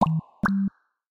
Samsung Galaxy Bildirim Sesleri - Dijital Eşik
Drip Drop
drip-drop.mp3